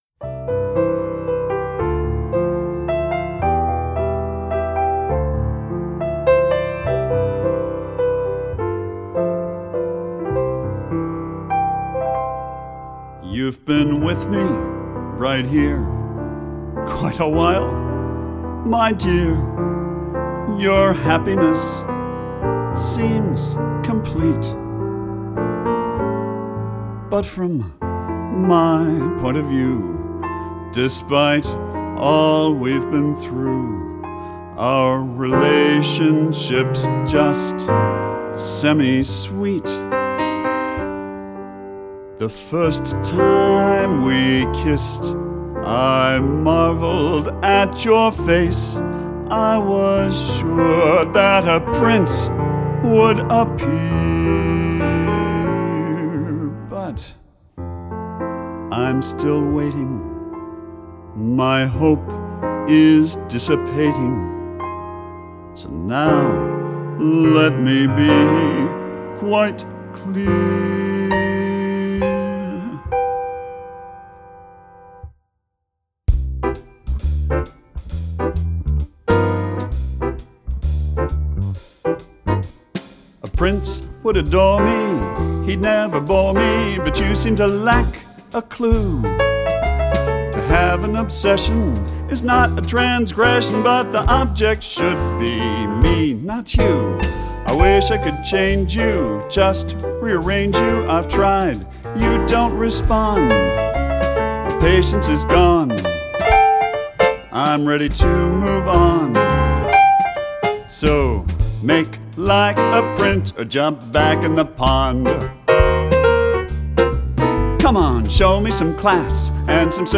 The singers perform with a jazz trio.